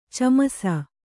♪ camasa